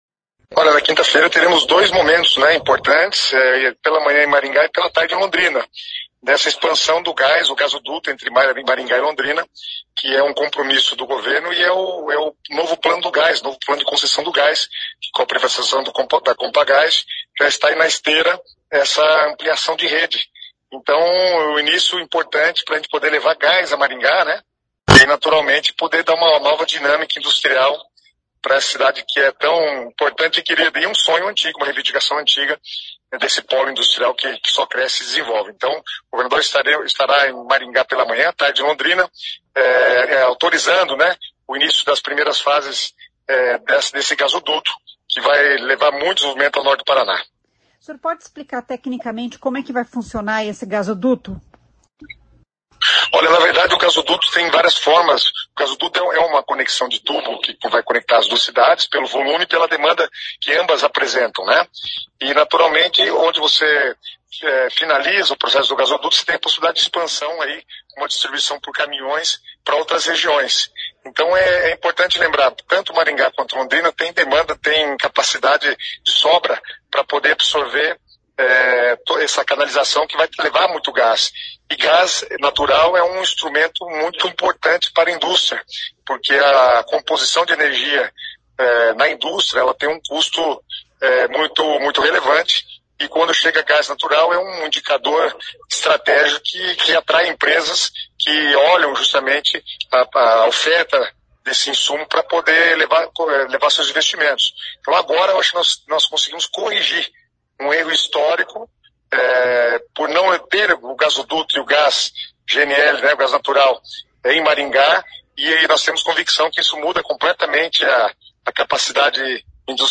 O secretário de Planejamento do Paraná, Guto Silva, explica que a rede de gás entre Maringá e Londrina vai promover o desenvolvimento industrial da região.